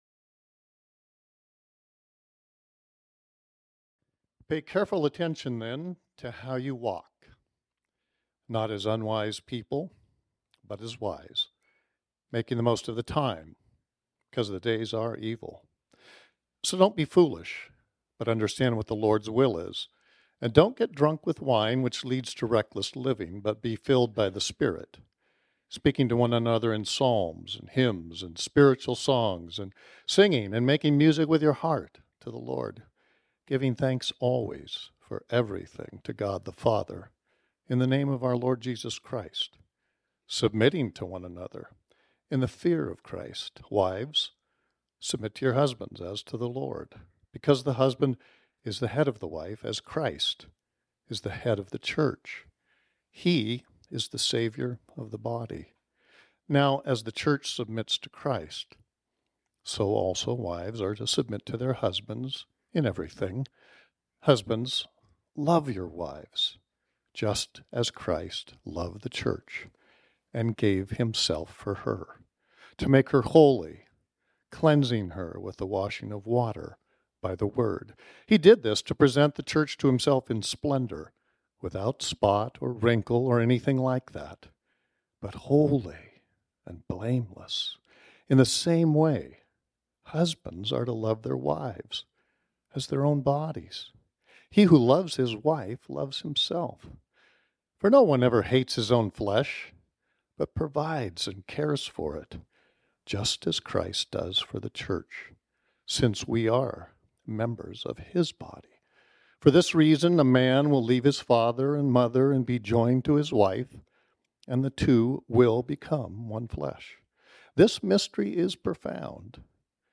This sermon was originally preached on Sunday, November 19, 2023.